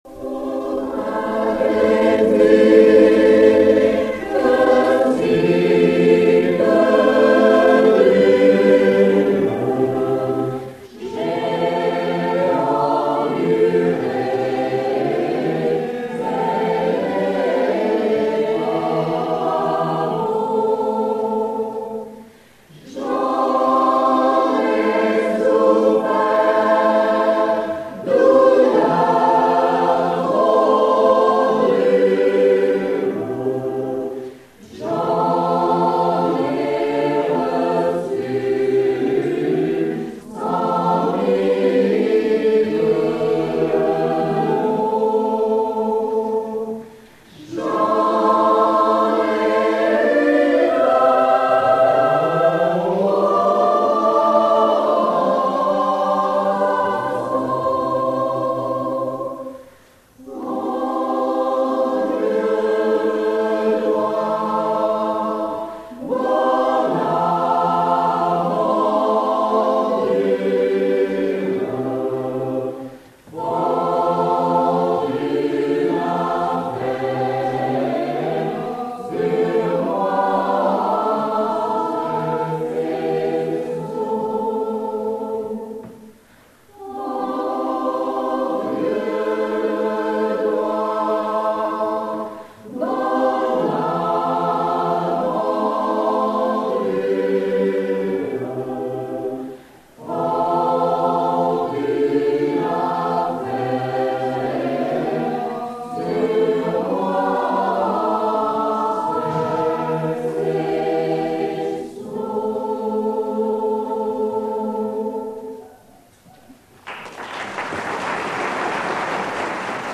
Concert "A Coeur Joie" 1 Juin 1979 CLUNY avec la Chorale "Clunysia",
l'Ensemble Vocal Mixte et l'Ensemble Vocal de l'ENSAM
Extaits du Concert de CLUNY